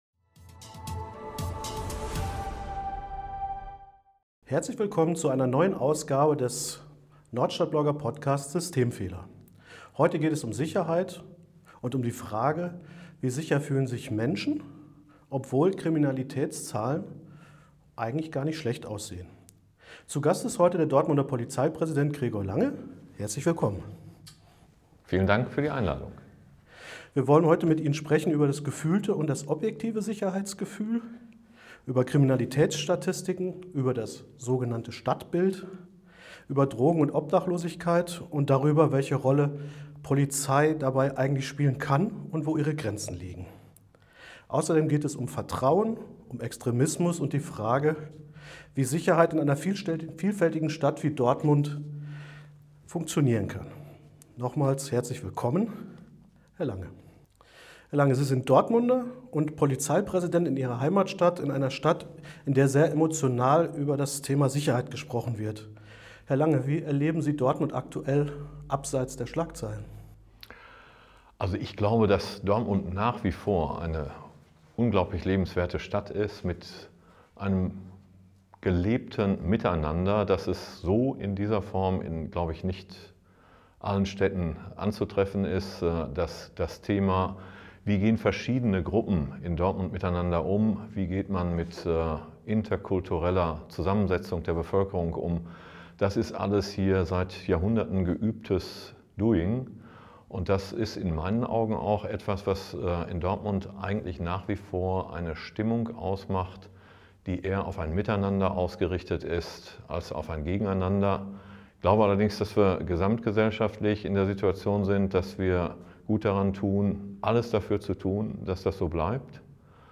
Der Polizeipräsident im Interview: Wie sicher ist Dortmund wirklich, Gregor Lange?